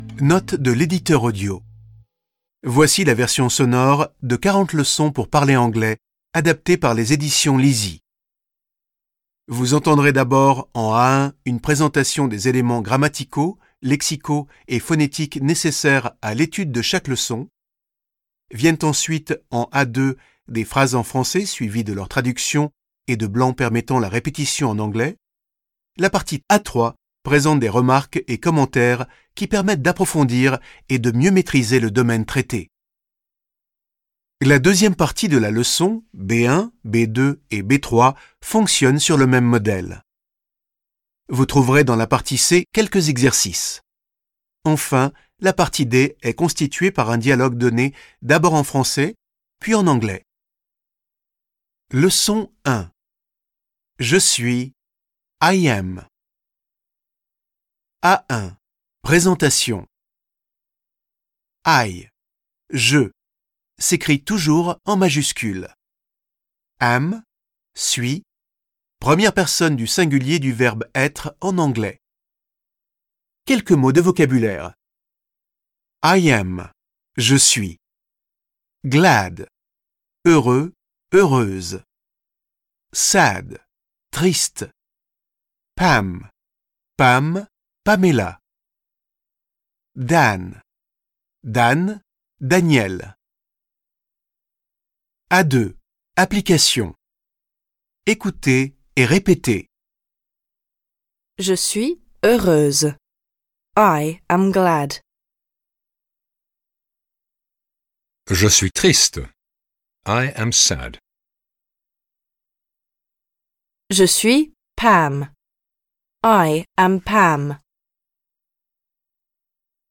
En A2, vous découvrez des phrases en français et leur traduction en anglais à répéter.
La partie B est structurée comme la partie A. La partie C comporte quelques exercices d'application. La partie D se compose d'un dialogue donné en français et en anglais qui met en situation les points étudiés dans les parties précédentes.